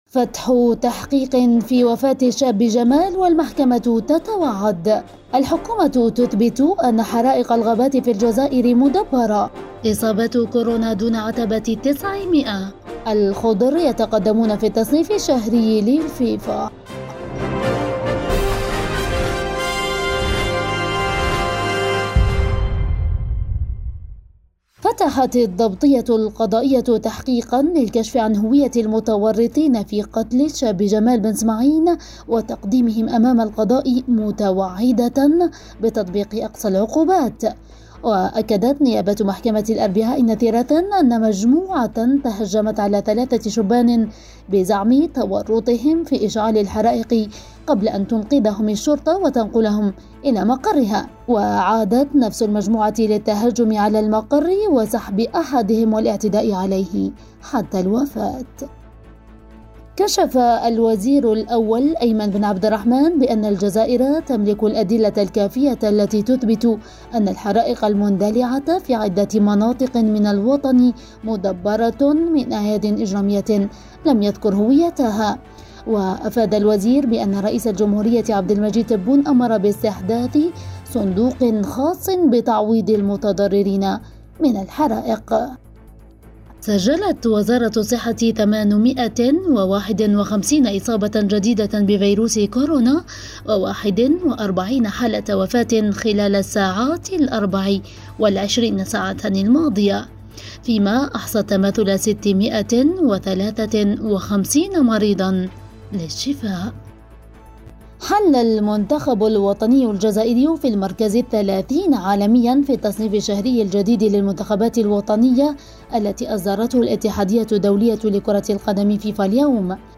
النشرة اليومية